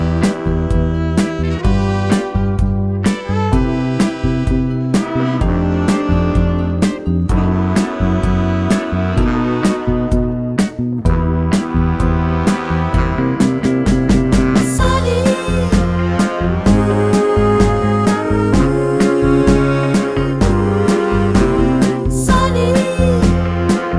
no Backing Vocals Soul / Motown 2:52 Buy £1.50